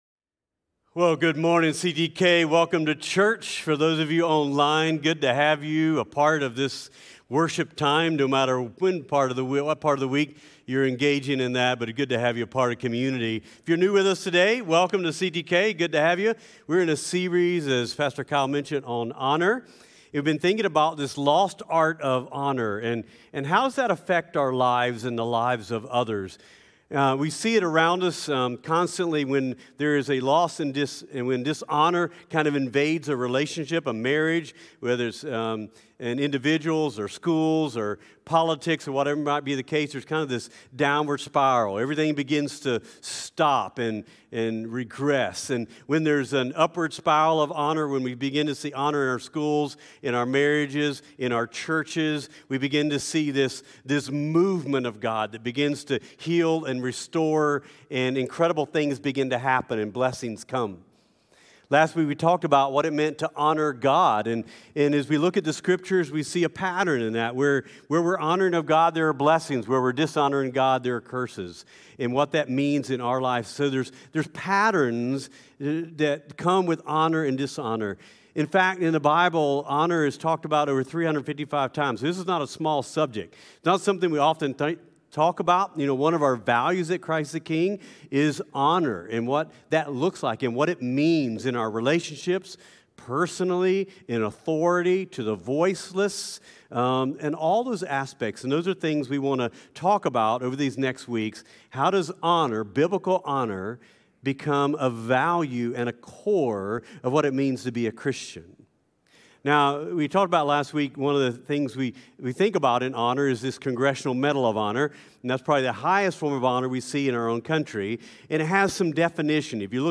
CtK-Sermon-Audio.mp3